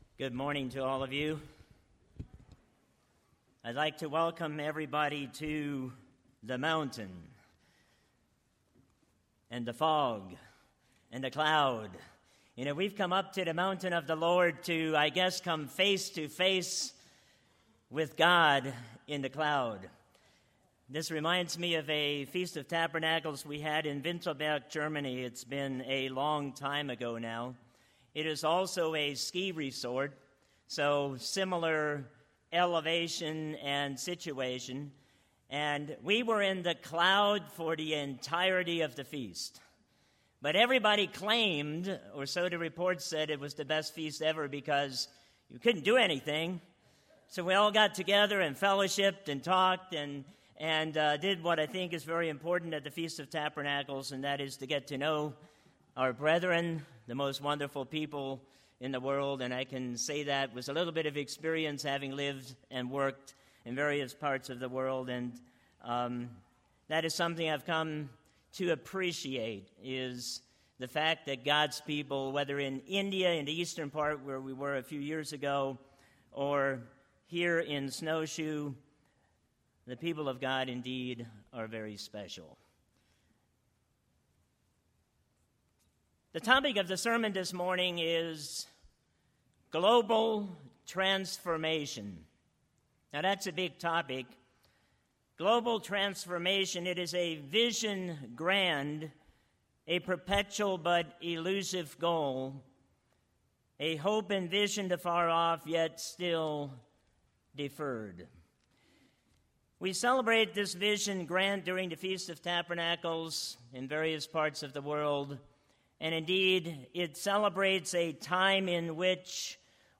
This sermon was given at the Snowshoe, West Virginia 2018 Feast site.